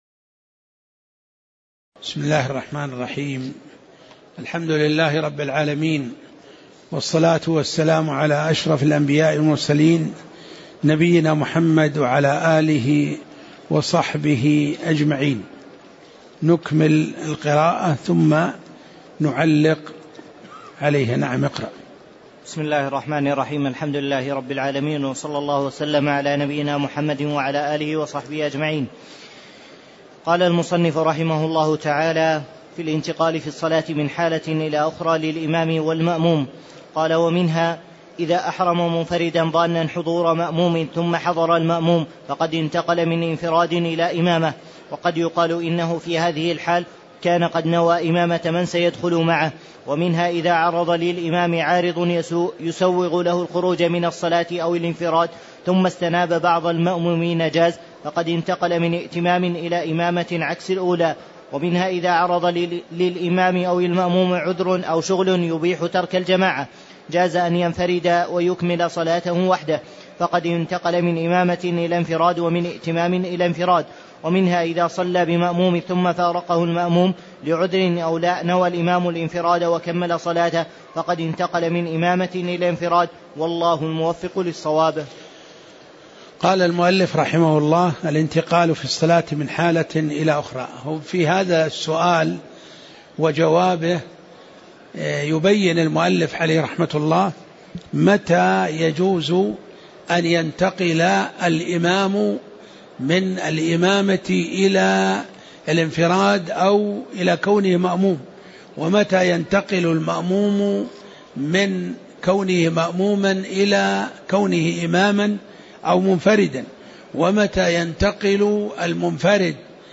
تاريخ النشر ١٦ شوال ١٤٣٨ هـ المكان: المسجد النبوي الشيخ